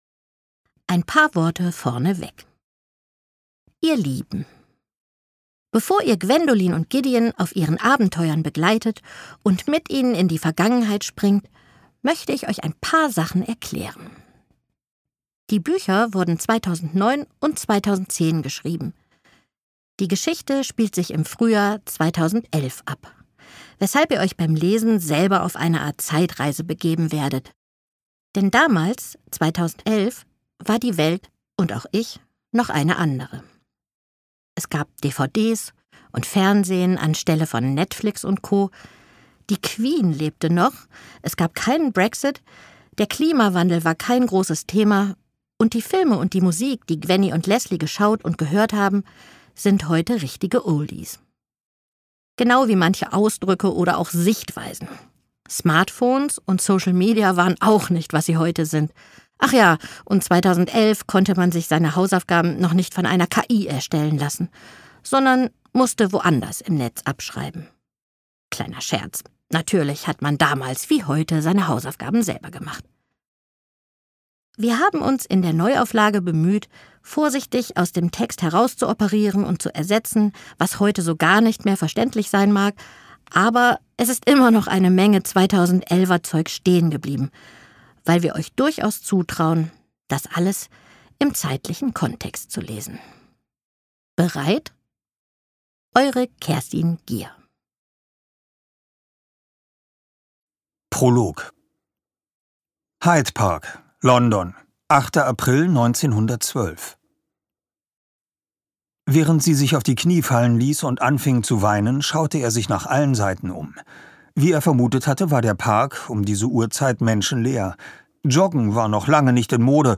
junge Stimme und ihre große Stimmenvielfalt machen ihre Lesungen zu einem Erlebnis - egal in welchem Zeitalter.
Der Name de Villiers wird in Übereinstimmung mit Kerstin Gier in diesem Hörbuch korrekt englisch ausgesprochen, da es sich zwar ursprünglich um ein französisches Geschlecht handelte, der englische Zweig der Familie aber mittlerweile englisch ausgesprochen wird.
Oliver Rohrbeck, eine der bekanntesten deutschen Hörbuch-Stimmen, ist bereits seit seiner Kindheit als Sprecher tätig.